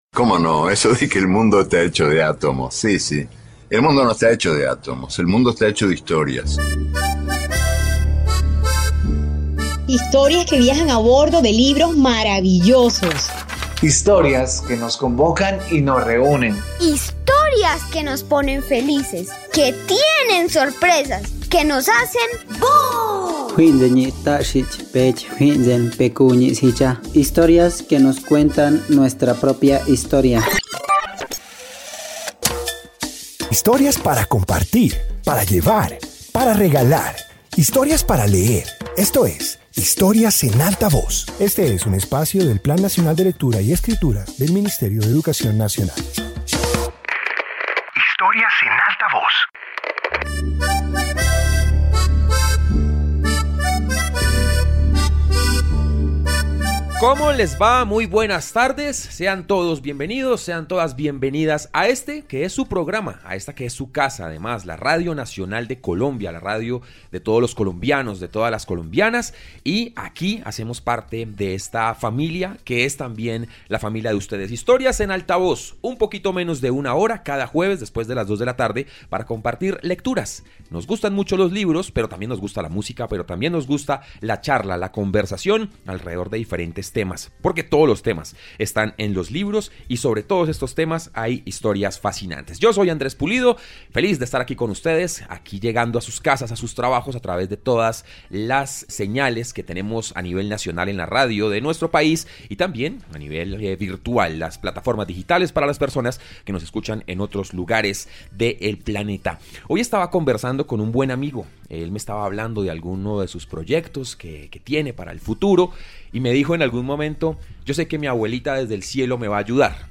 Introducción Este episodio de radio reúne lecturas sobre las etapas del recorrido vital. Presenta historias que evocan cambios, aprendizajes y momentos significativos que acompañan la existencia.